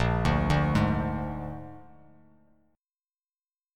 Listen to Bb7 strummed